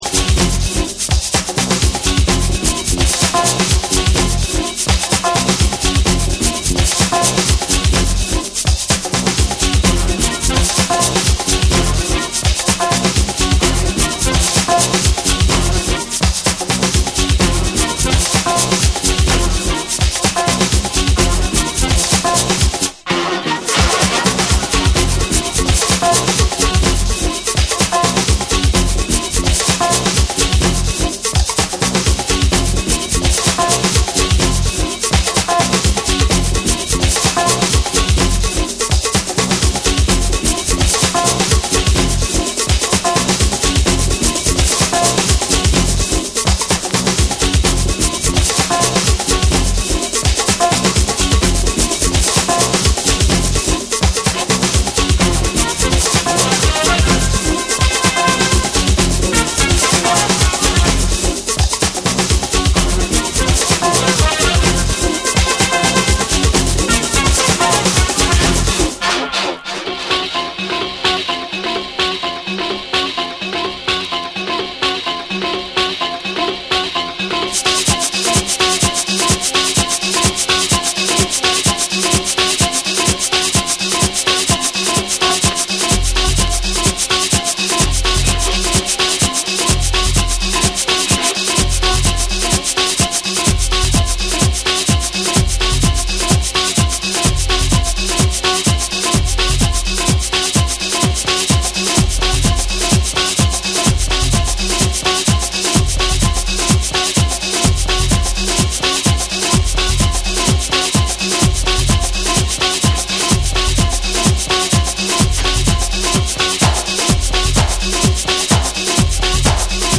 サックス等の生楽器とエレクトリックな音色が相まった
TECHNO & HOUSE / DISCO DUB